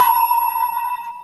High Bell Sound
cartoon